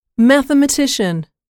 단어번호.0652 대단원 : 3 소단원 : a Chapter : 03a 직업과 사회(Work and Society)-Professions(직업) 출제년도 : 14 mathematician [mӕθəmətíʃən] 명) 수학자 mp3 파일 다운로드 (플레이어바 오른쪽 아이콘( ) 클릭하세요.)
mathematician.mp3